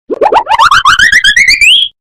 Goofy Ahh Falling Bouton sonore
Sound Effects Soundboard1 views